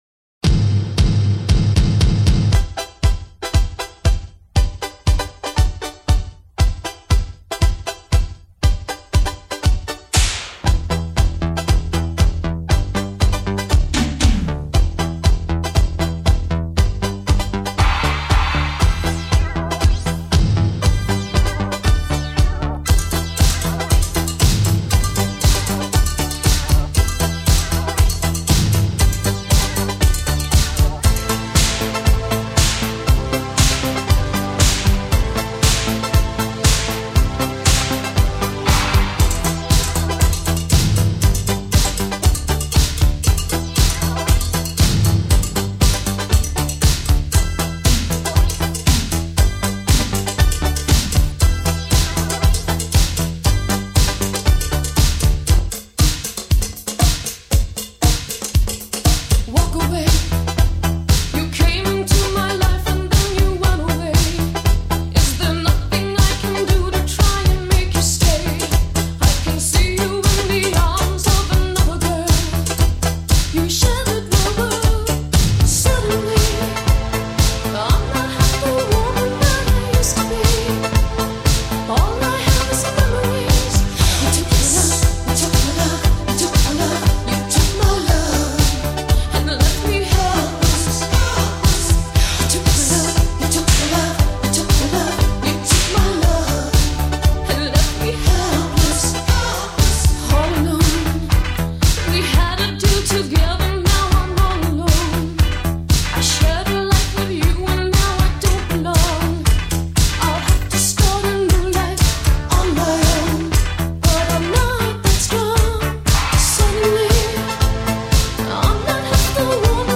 Filed under blabla, disco, italo